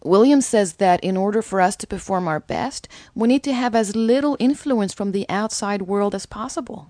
Name: Sydelle Williams Age: 25 Occupation: DITR employee Listen to Sydelle's voice